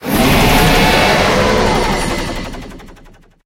Cri d'Éthernatos Infinimax dans Pokémon HOME.